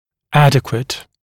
[‘ædɪkwət][‘эдикуэт]соответствующий, соразмерный, адекватный, отвечающий требованиям